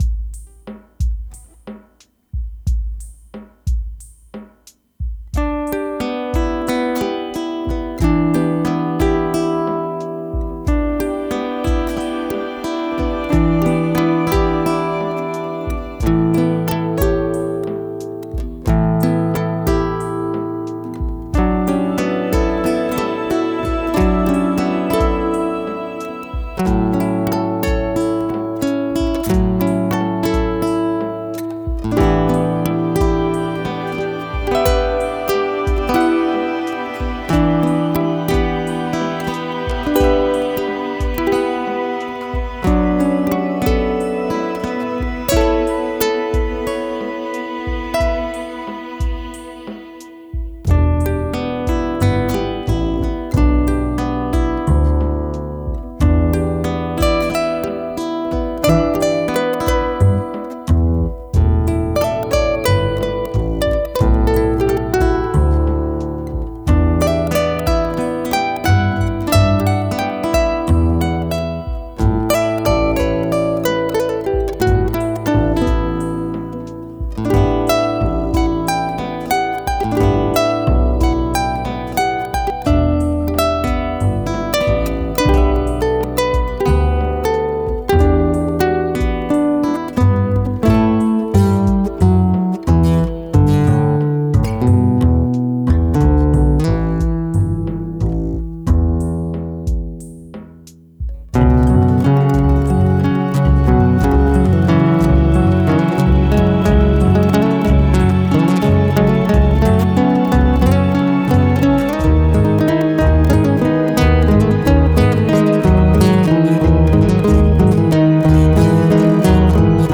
Akustische Musik zum Entspannen.